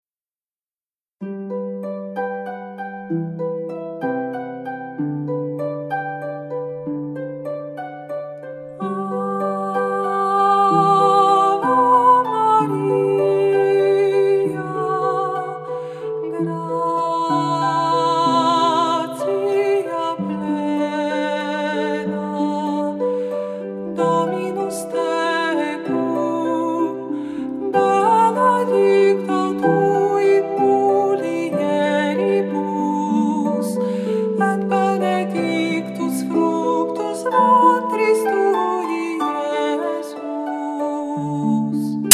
w wykonaniu sióstr: